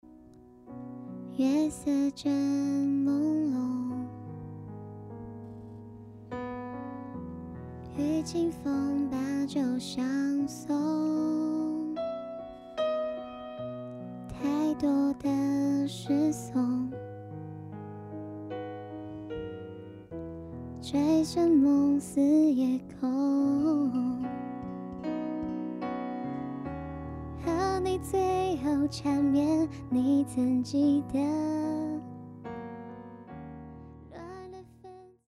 歌曲调式：升C大调 干声数量：15轨道